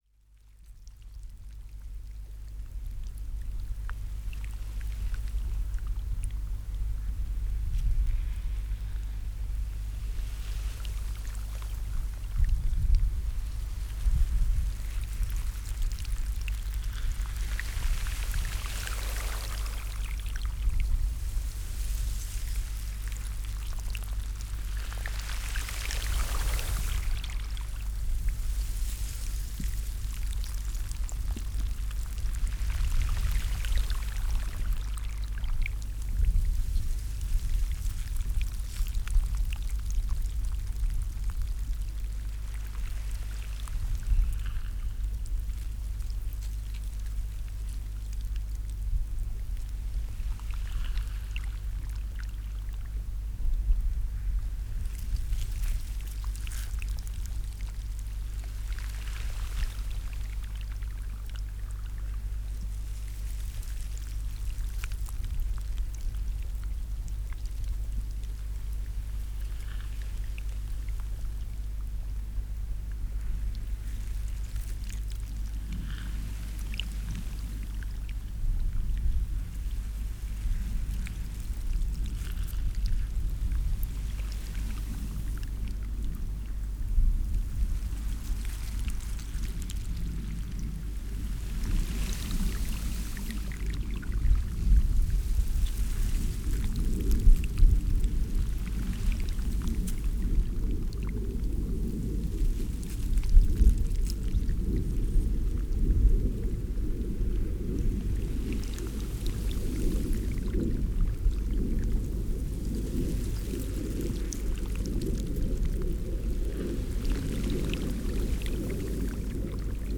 Rode NT1A in a ORTF setup.
101227, moving icy water I